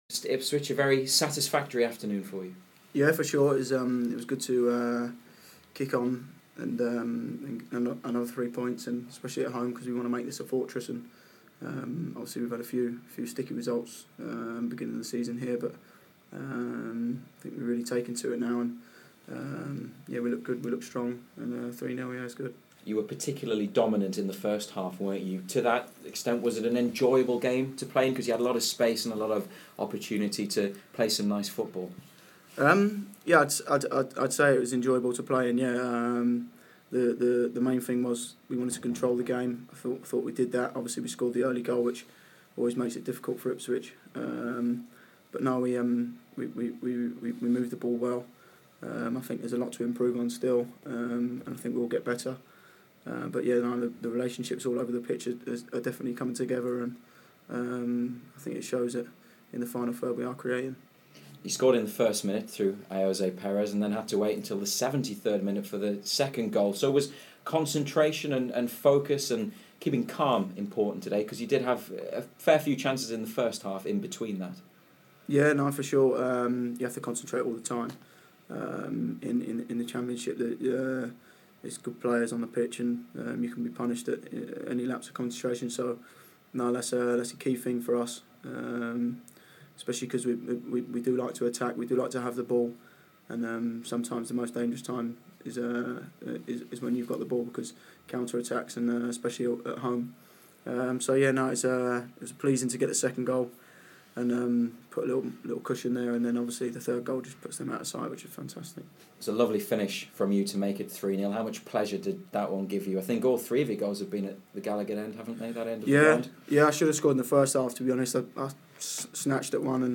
Matt Ritchie spoke to BBC Newcastle after scoring in United's 3-0 win against Ipswich Town.